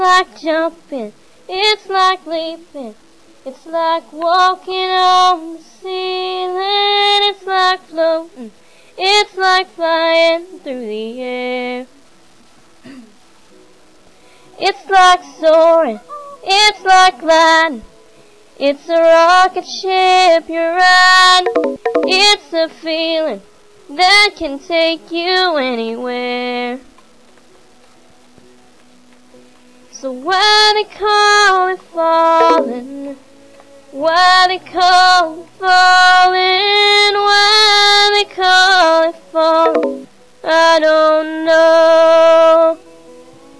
some of the DJ's singing on a live broadcast.